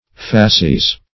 Facies \Fa"ci*es\, n. [L., from, face.